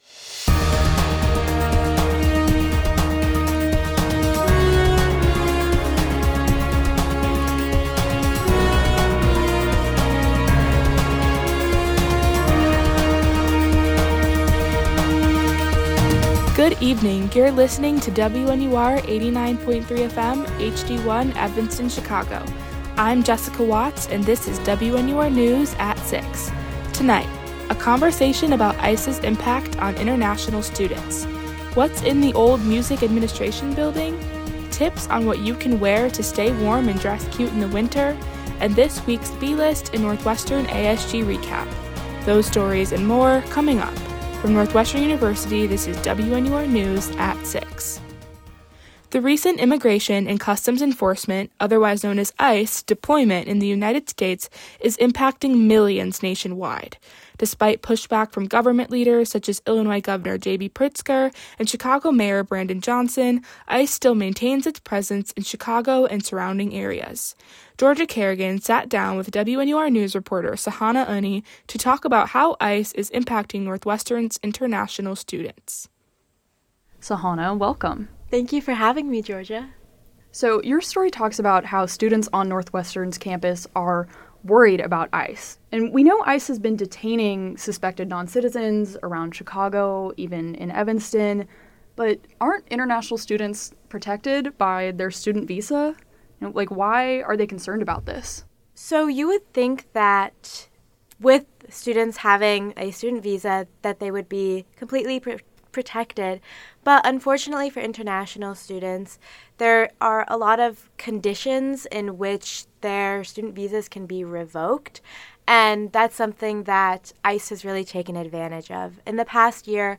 January 26, 2026: ICE and international students, an empty campus building, winter fashion tips, the B-List and an ASG recap. WNUR News broadcasts live at 6 pm CST on Mondays, Wednesdays, and Fridays on WNUR 89.3 FM.